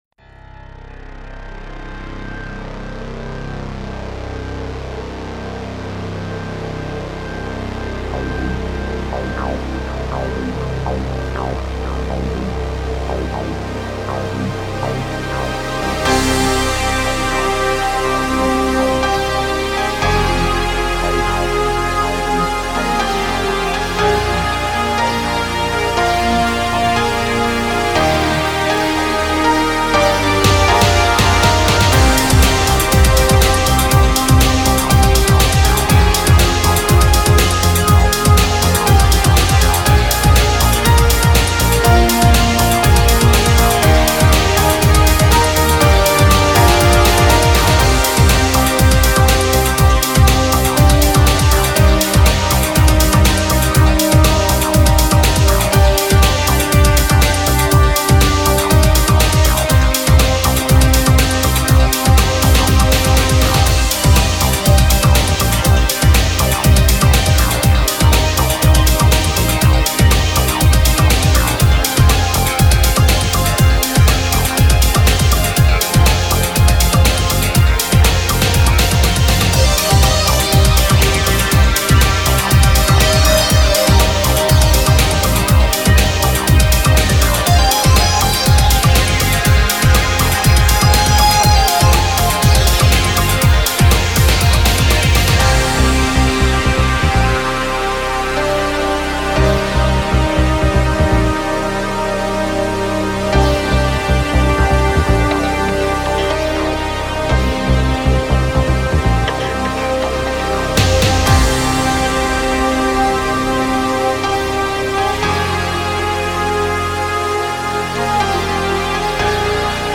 Home > Music > Electronic > Bright > Dreamy > Running